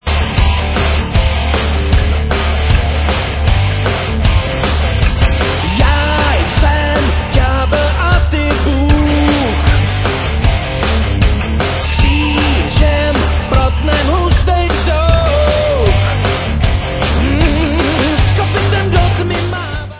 vocals, guitars
drums, vocals
keyboards
bass